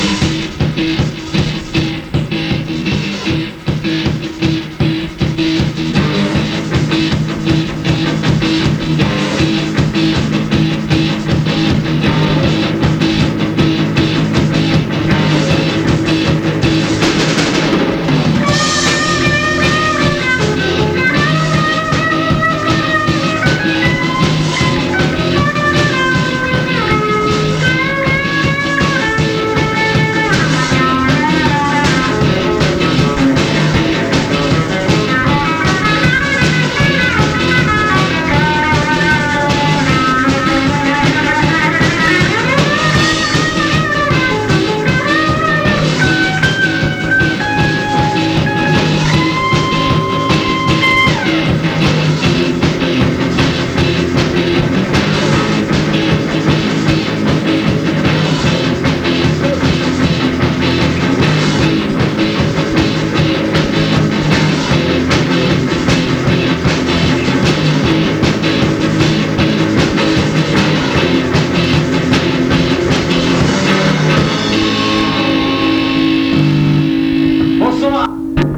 basse
batterie
guitare
chant
guitare rythmique